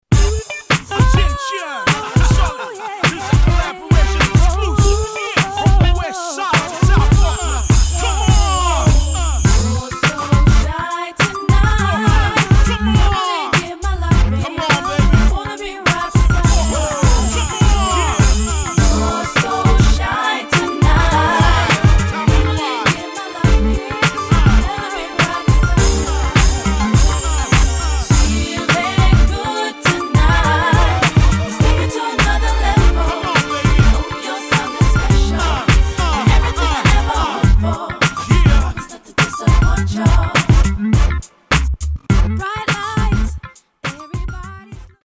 Genre: South Seas, world music.